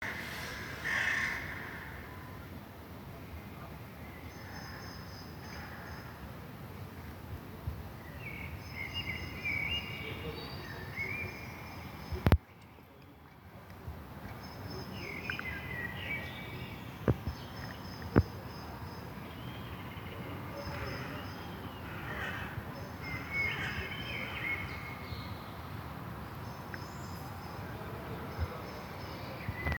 (933.04 KB, birb.mp3)
Закат, птички поют.